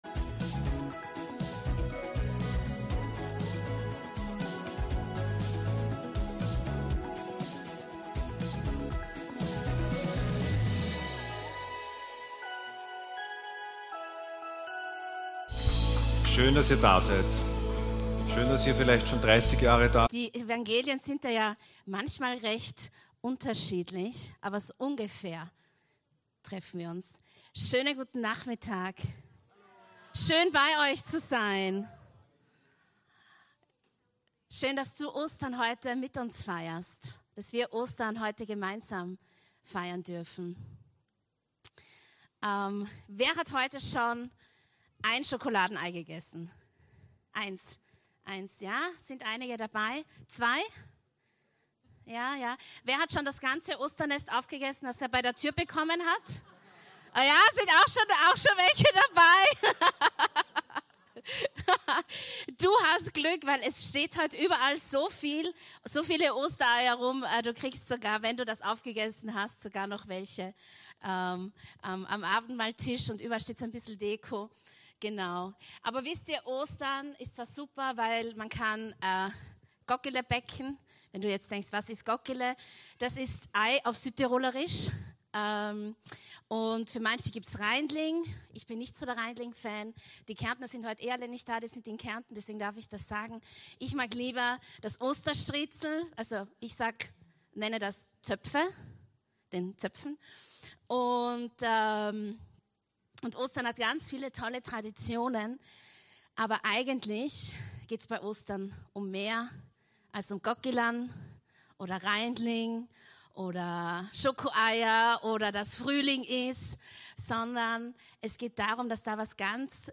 Ostergottesdienst aus der LIFE Church Wien.